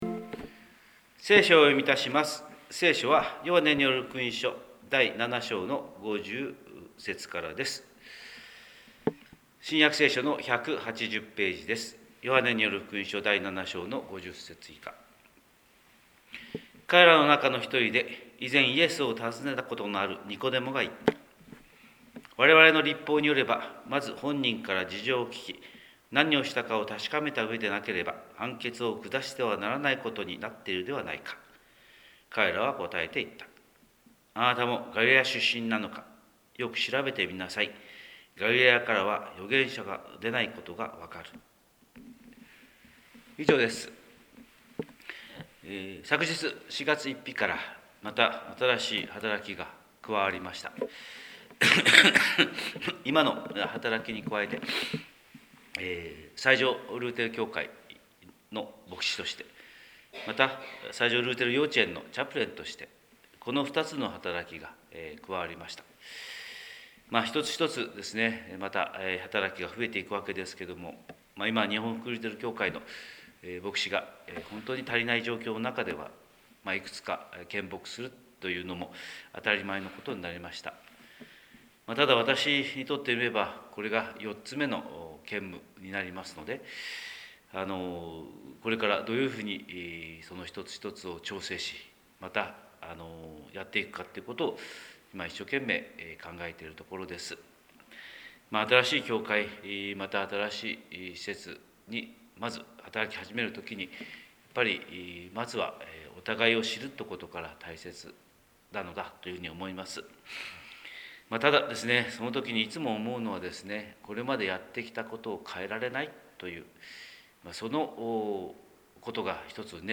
神様の色鉛筆（音声説教）
広島教会朝礼拝250425